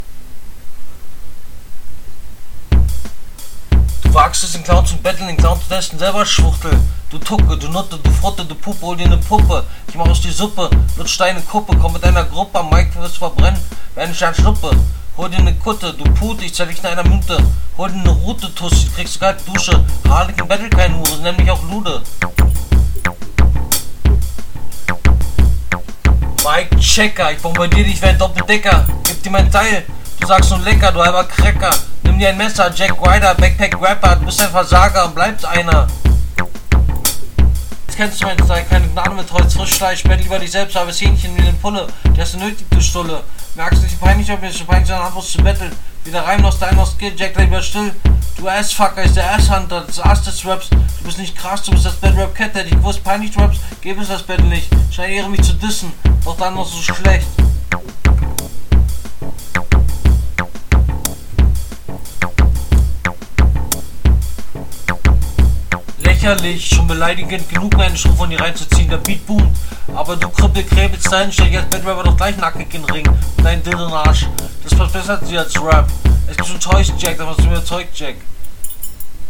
Quali wieder gleich wie in den anderen Runden. Offbeat, Stimme und Flow völlig daneben.